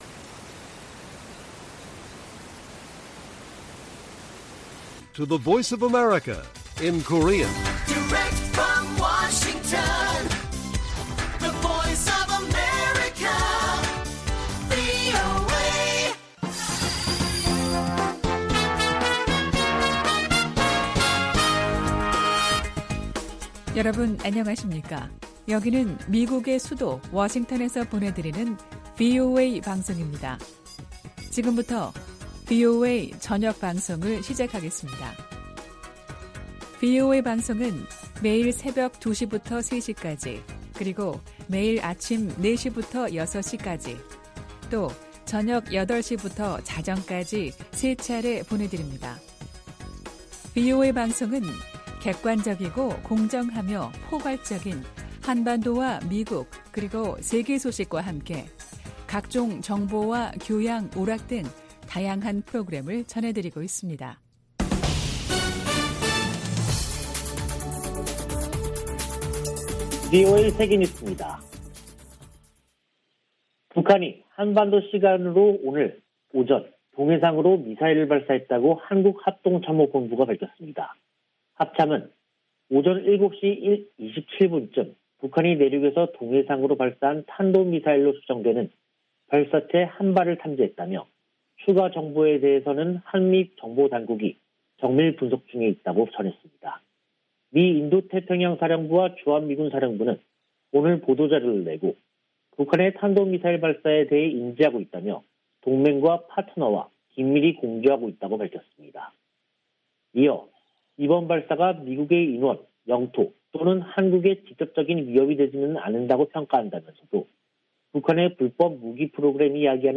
VOA 한국어 간판 뉴스 프로그램 '뉴스 투데이', 2022년 1월 11일 1부 방송입니다. 북한이 엿새 만에 또 다시 탄도미사일 추정체를 발사했습니다. 유엔 안보리가 지난 5일의 북한 탄도미사일 발사 문제를 논의했습니다. 미 국방부는 극초음속 미사일을 발사했다는 북한의 주장에 세부사항을 평가 중이라고 밝혔습니다.